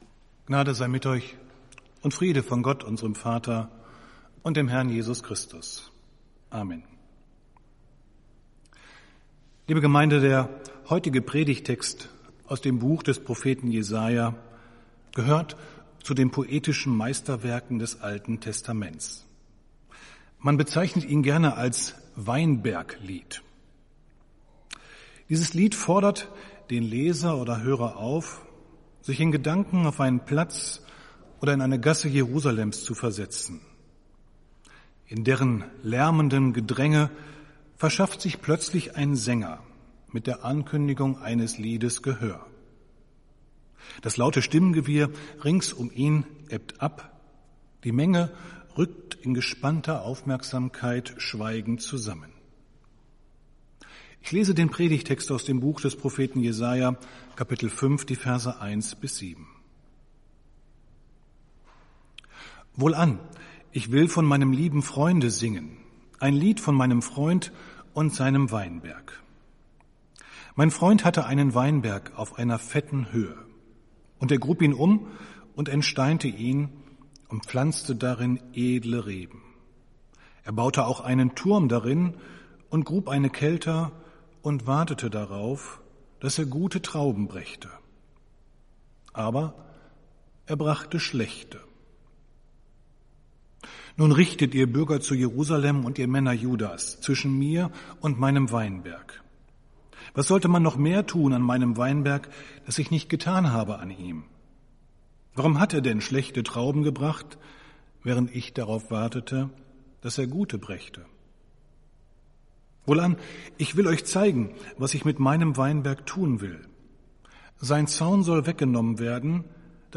Predigt des Gottesdienstes aus der Zionskirche vom Sonntag, 28.02.2021